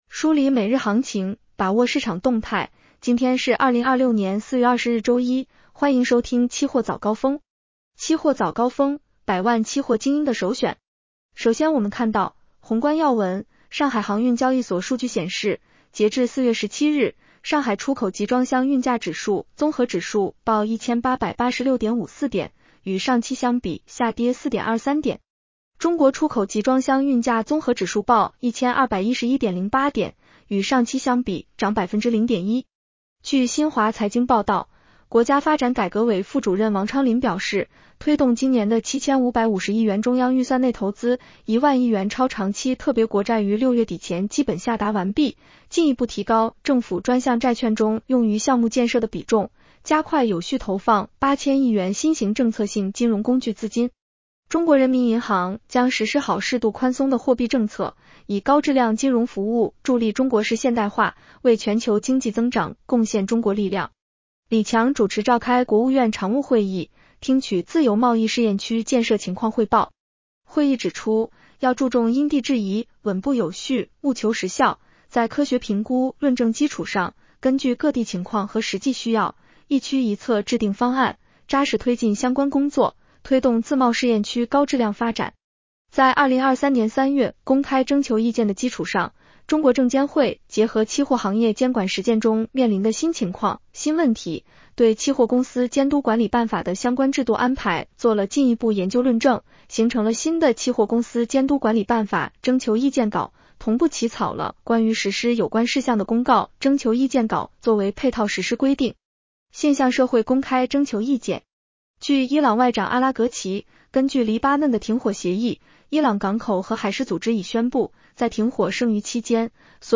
期货早高峰-音频版